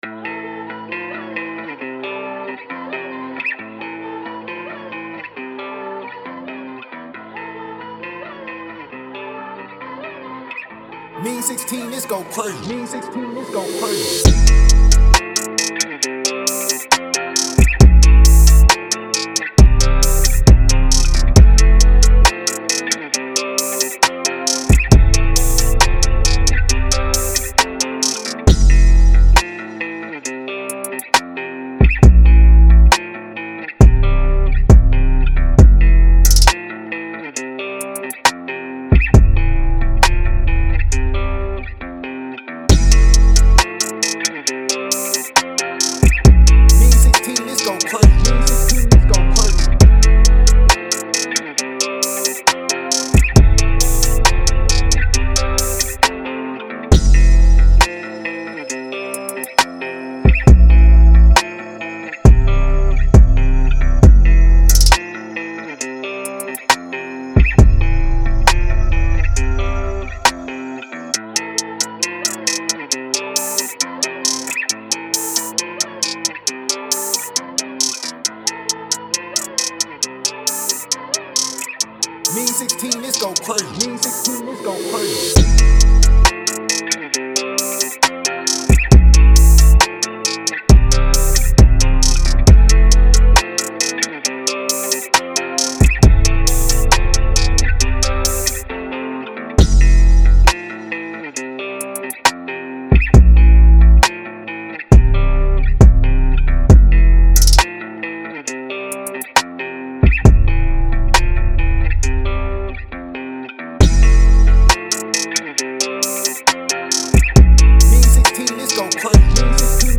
A-Min 135-BPM